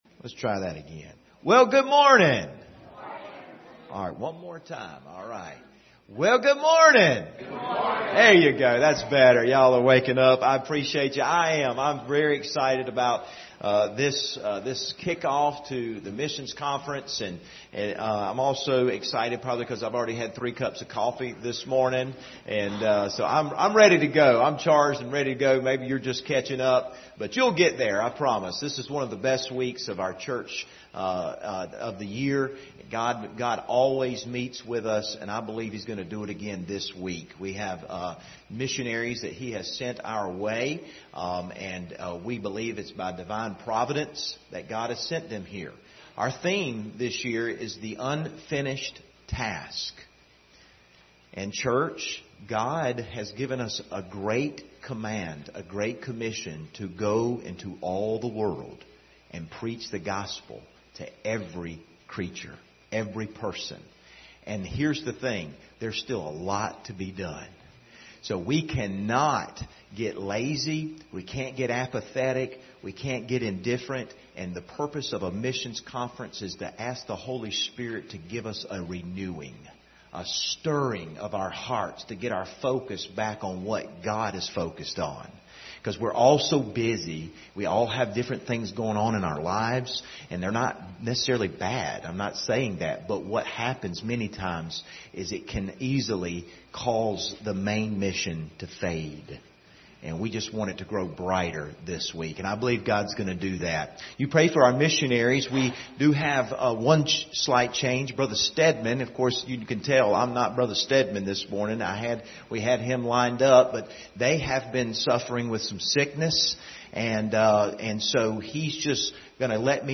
Series: 2023 Missions Conference Service Type: Sunday School Hour View the video on Facebook Topics